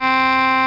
Crumhorn Alto Sound Effect
Download a high-quality crumhorn alto sound effect.
crumhorn-alto.mp3